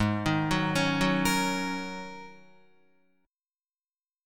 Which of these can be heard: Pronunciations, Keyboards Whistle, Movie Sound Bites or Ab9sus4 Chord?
Ab9sus4 Chord